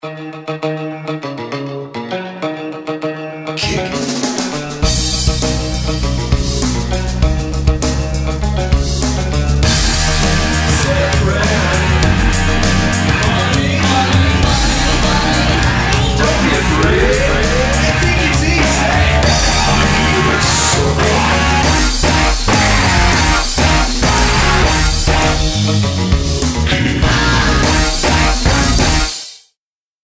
TV Themes